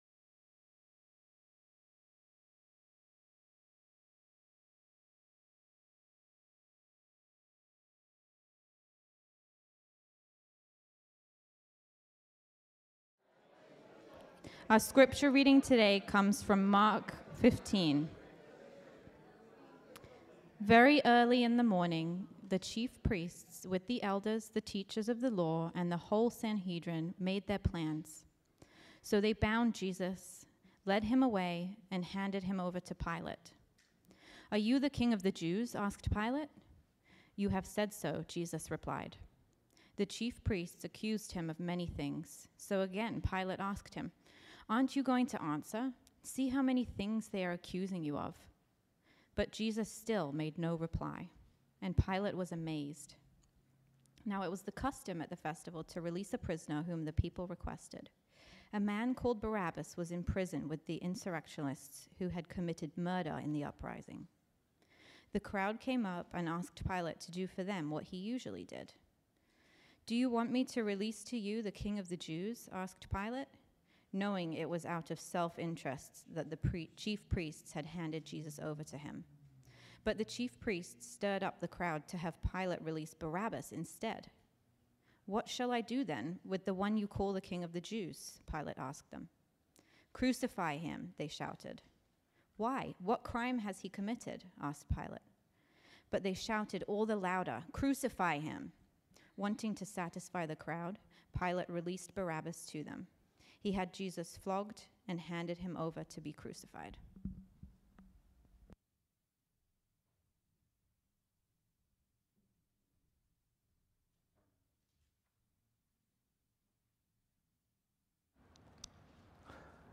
Grace Sermons | Grace Evangelical Free Church